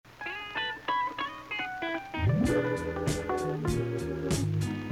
Just for fun I overlayed the 2.